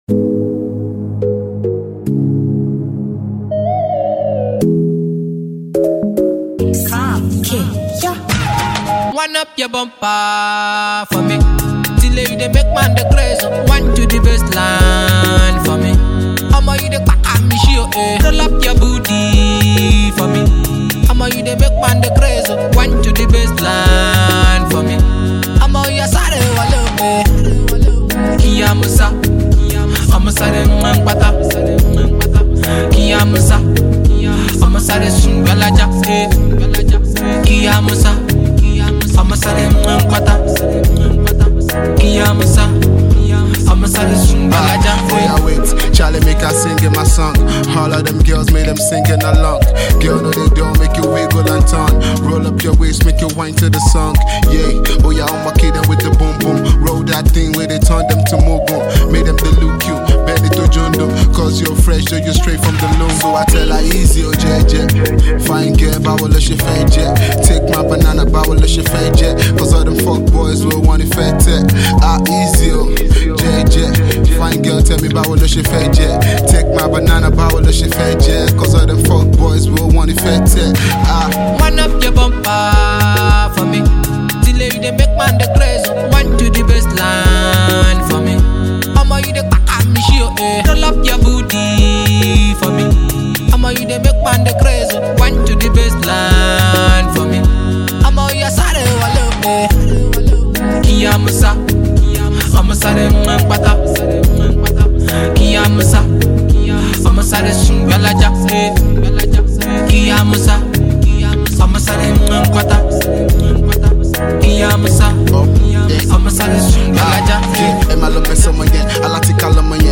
Afrobeat is winning globally
a prolific new school rapper
vocalist and ace producer